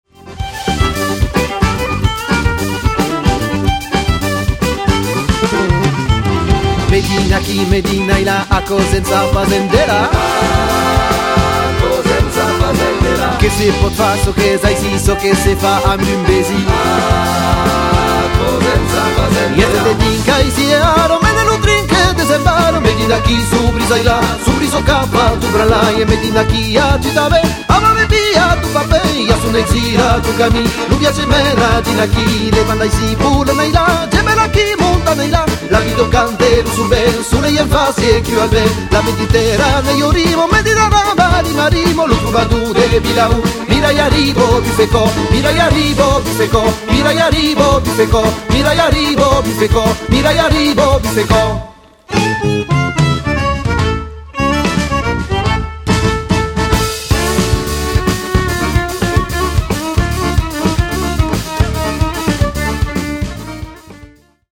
Accordéon